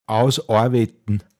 Pinzgauer Mundart Lexikon
Details zum Wort: ausårwetn. Mundart Begriff für ausarbeiten, sich verausgaben